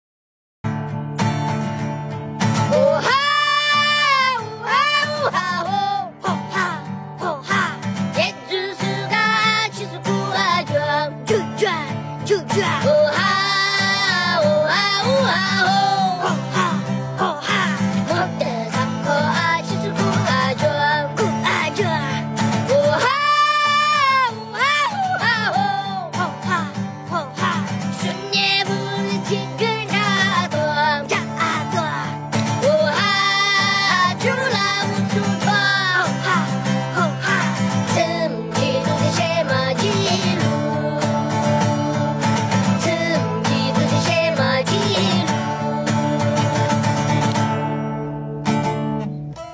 Muyunbrothers___Ouah_Ahh_3_Little_Guys_Singing.mp3